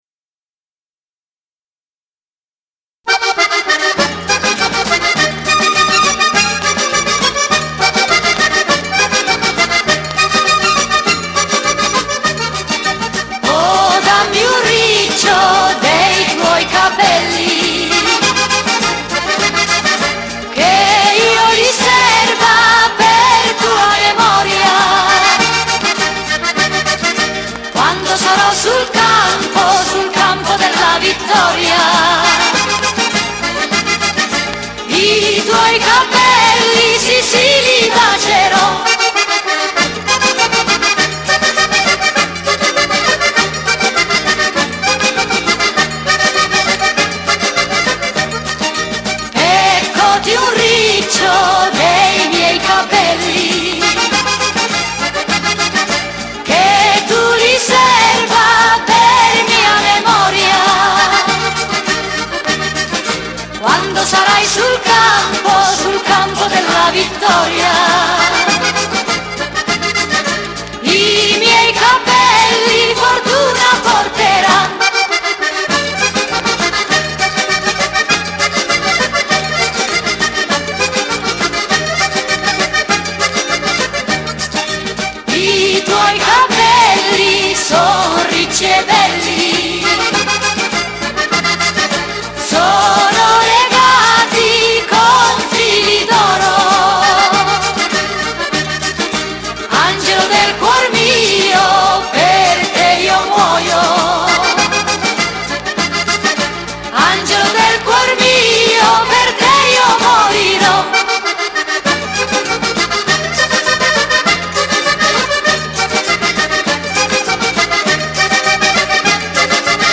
motivo popolare